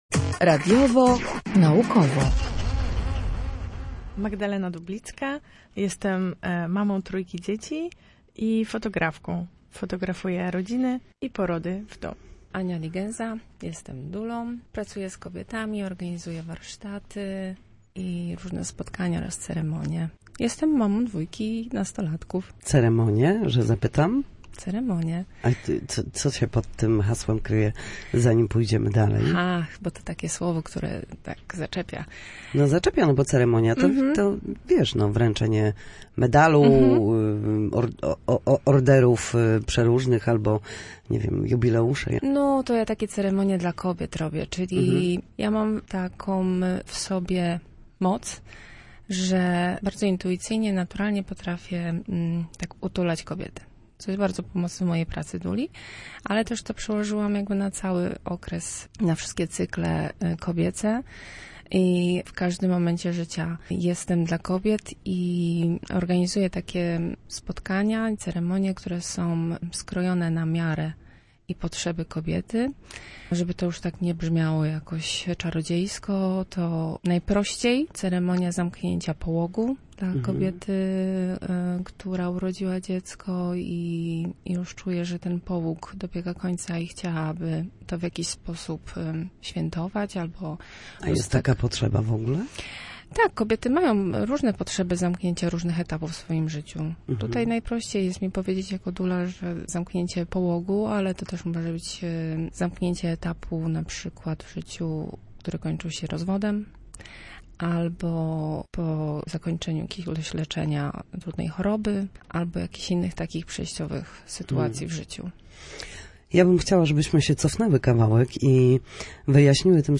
Na naszej antenie mówiły między innymi o towarzyszeniu, intymności, zdjęciach porodu, bliskości i spotkaniach z człowiekiem oraz Tygodniu Douli.